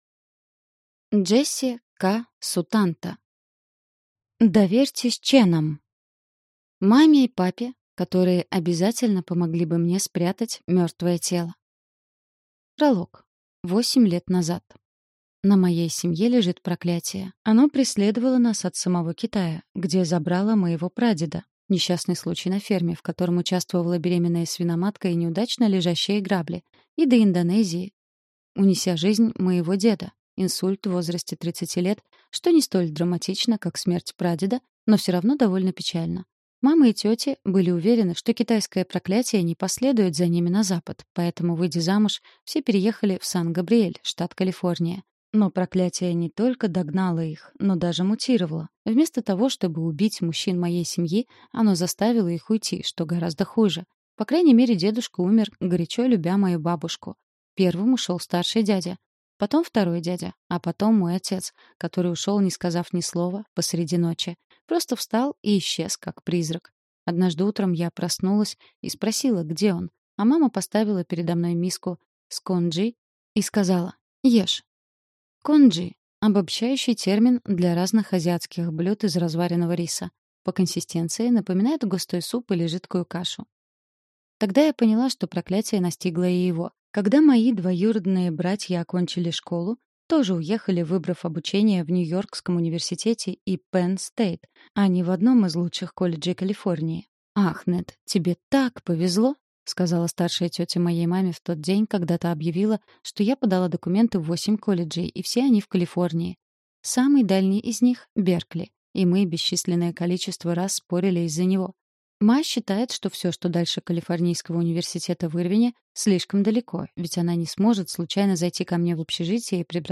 Аудиокнига Доверьтесь Ченам | Библиотека аудиокниг